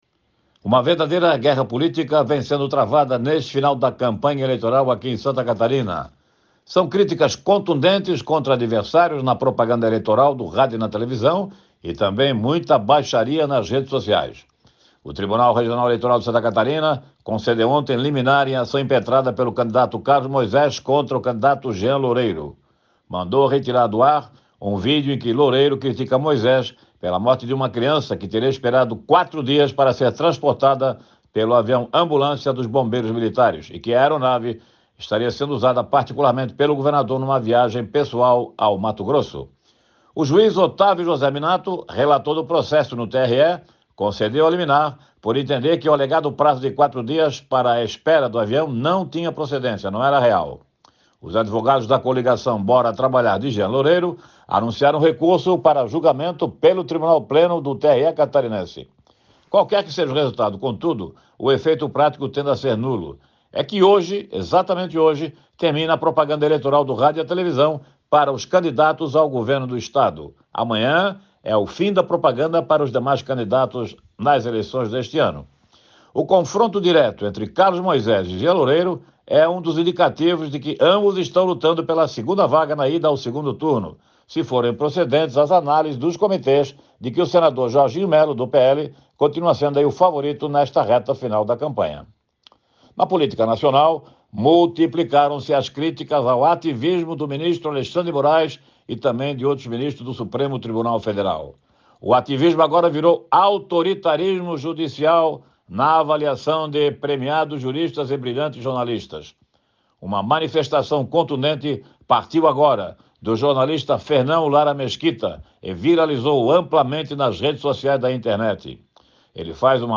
Jornalista ressalta sobre atos do Supremo Tribunal Federal e o cenário político catarinense a poucos dias das eleições